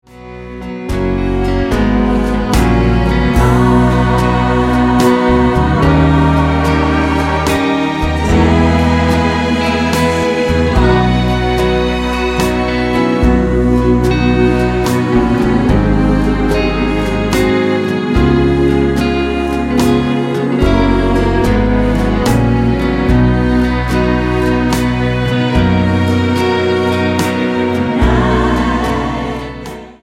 Tonart:B mit Chor